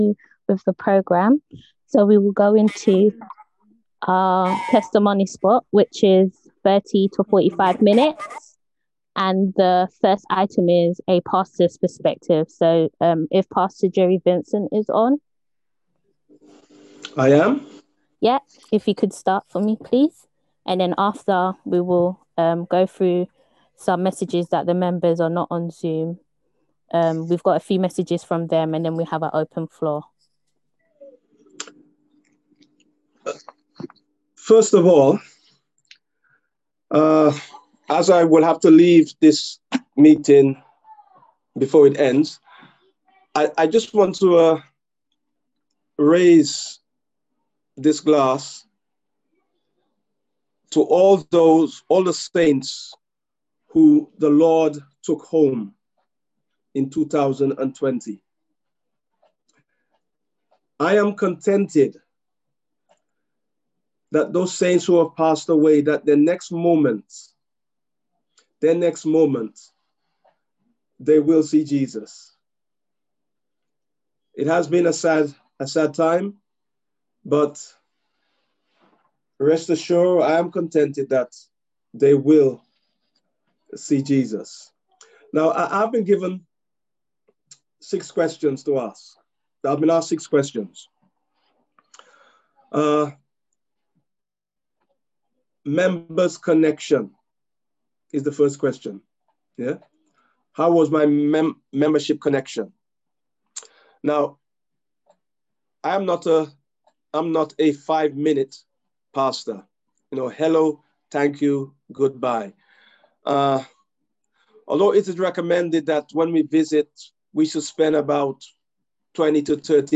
Testimonies_31.12.20.m4a
on 2021-01-01 - End of Year Service 31.12.20